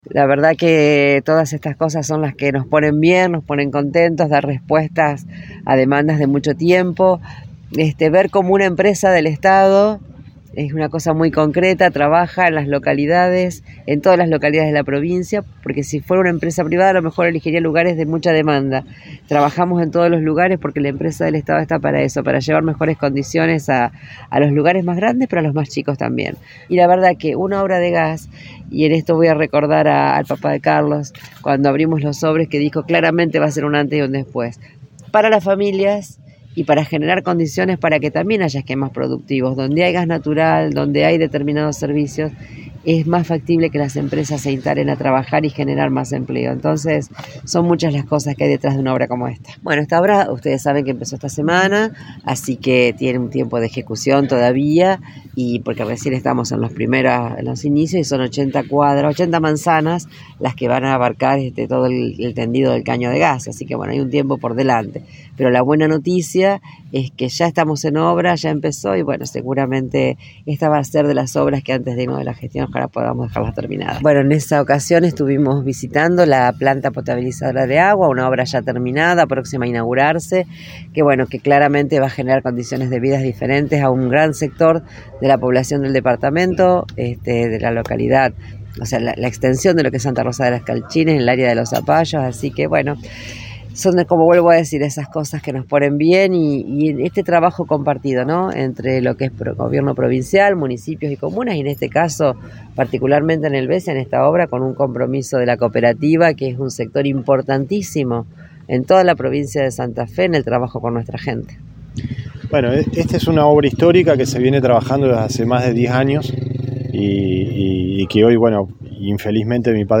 Declaraciones de Frana